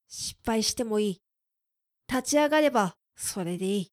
クール男性
dansei_shippaishitemoiitatiagarebasoredeii.mp3